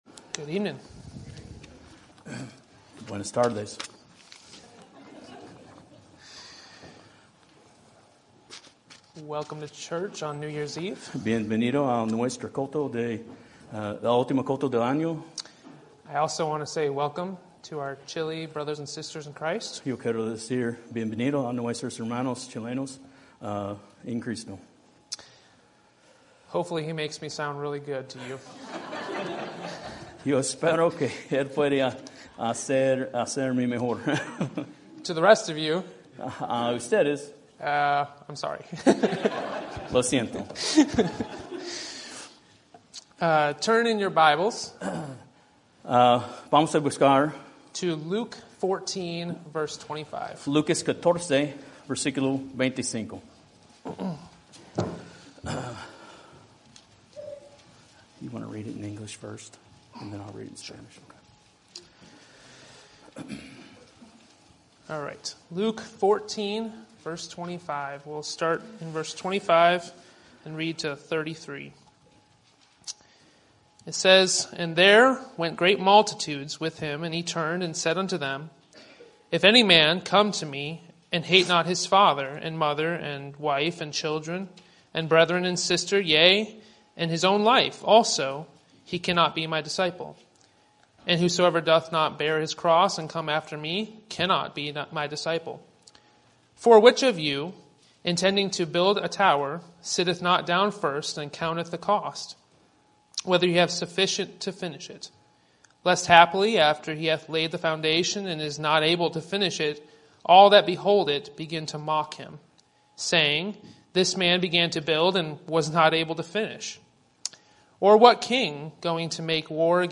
Sermon Topic: General Sermon Type: Service Sermon Audio: Sermon download: Download (14.5 MB) Sermon Tags: Luke Goals Plans Cost